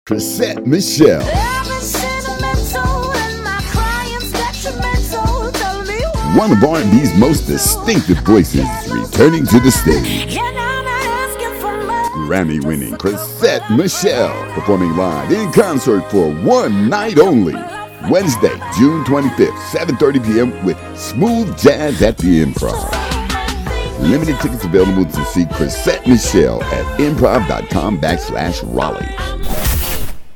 Chrisette Michele Radio Ad